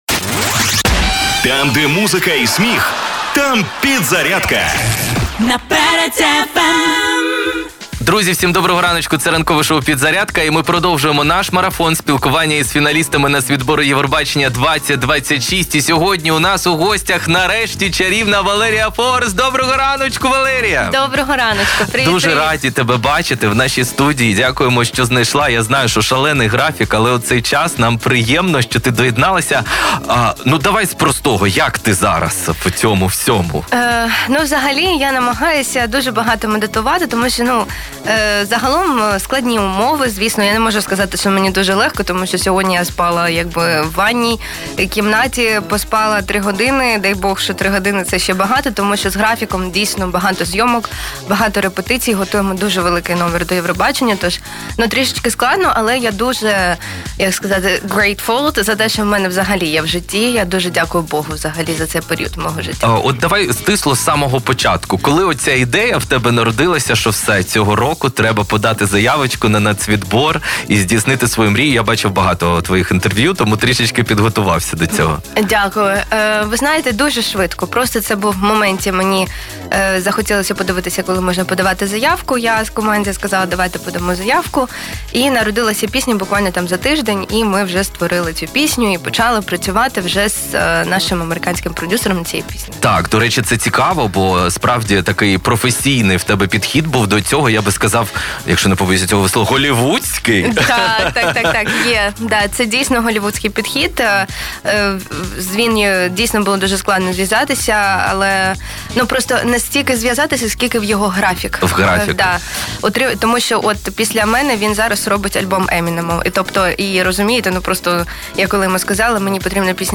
Як це було, слухайте в записі ефіру!